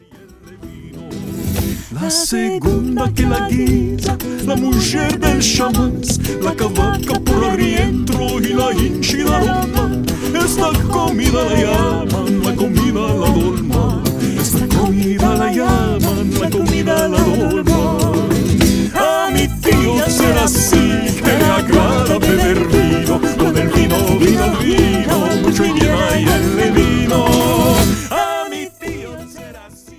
Island of Rhodes folk tune